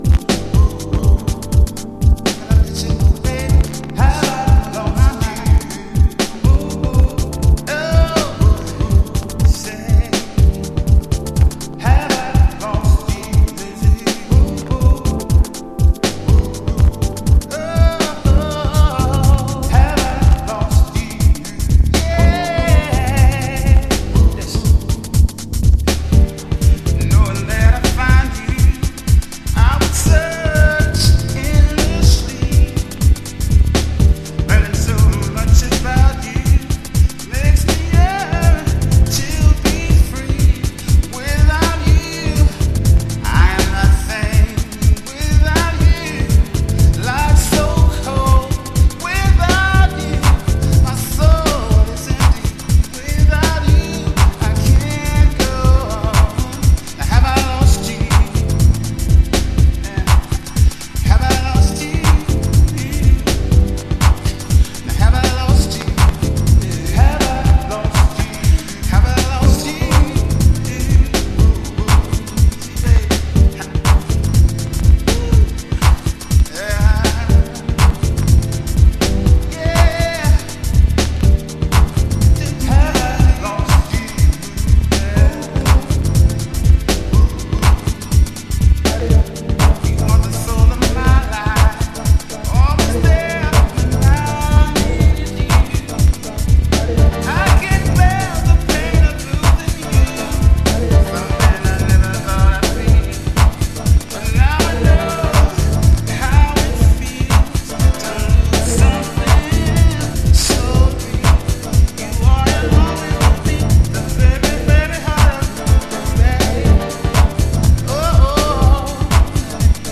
この時期の荒々しさと浮遊感が同居したサウンドはたまんないですね。
Extented Vocal Mix
Chicago Oldschool / CDH